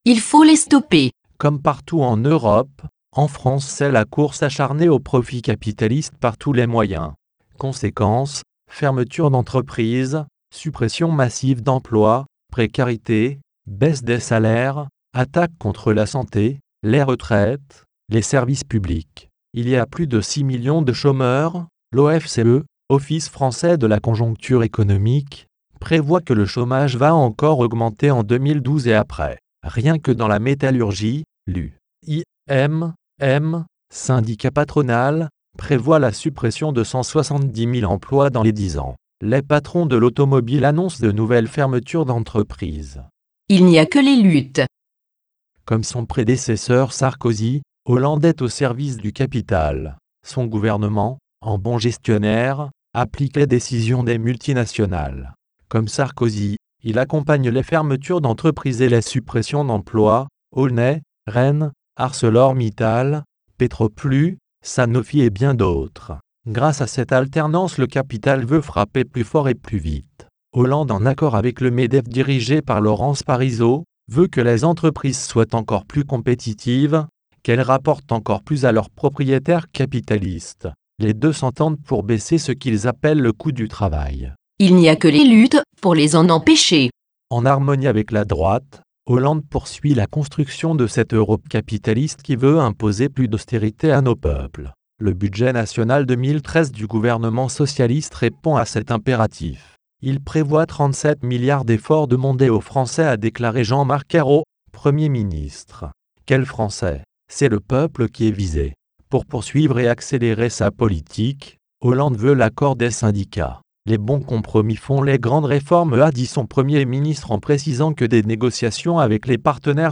Ecouter la lecture de ce tract